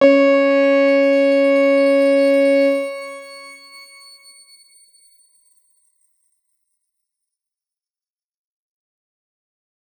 X_Grain-C#4-mf.wav